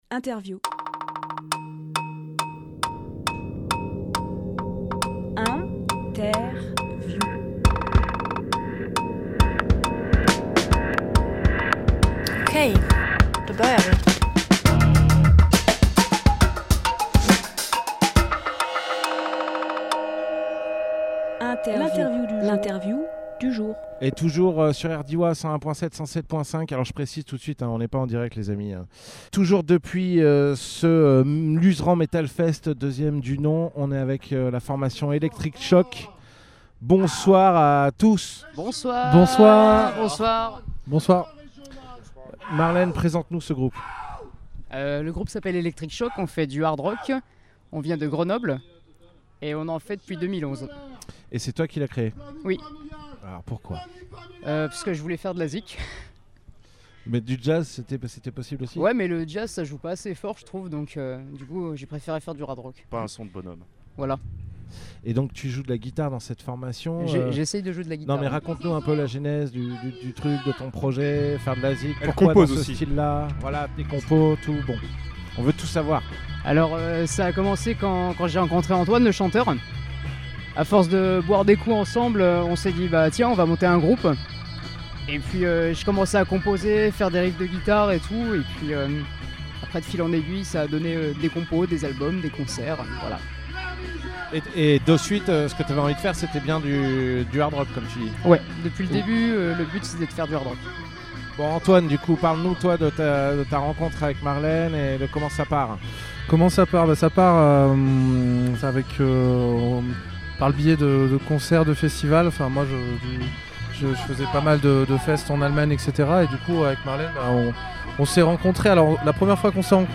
Emission - Interview Electric Shock (Luzerand Metal Fest 2023) Publié le 16 octobre 2023 Partager sur… Télécharger en MP3 C’était la deuxième édition du Luzerand Metal Fest, soirée de concerts punk, hardcore et metal en plein champs qui s’est tenue le 30 septembre 2023.